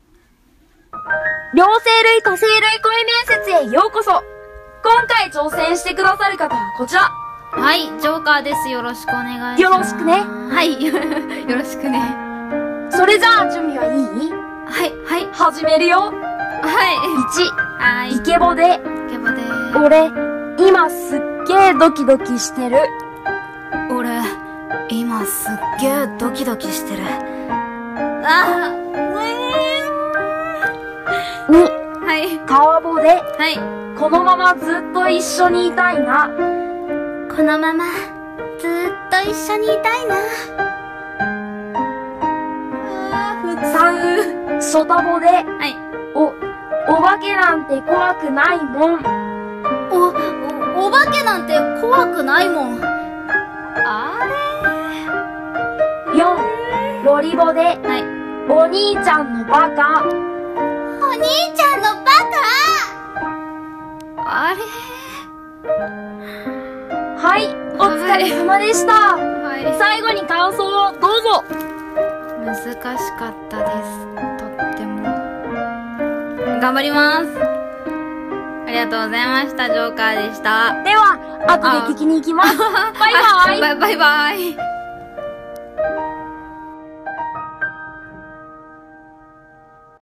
両声類・多声類声面接